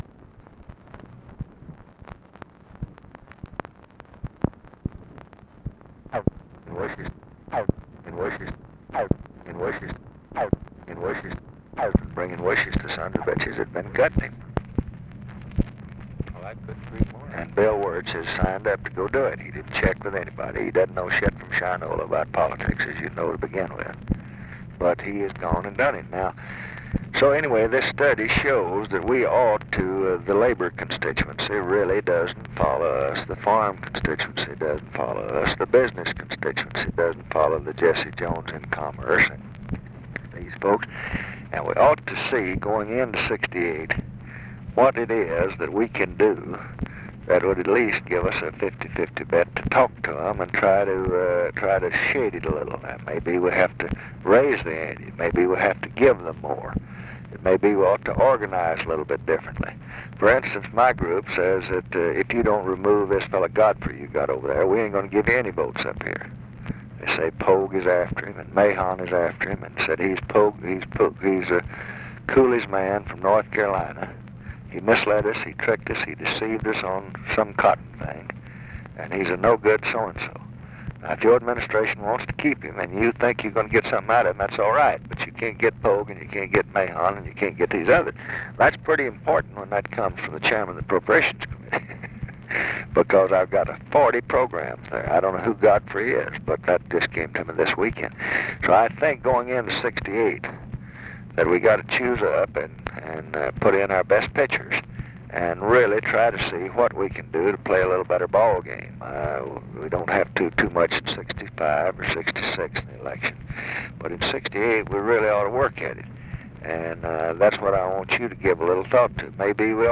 Conversation with ORVILLE FREEMAN, March 30, 1967
Secret White House Tapes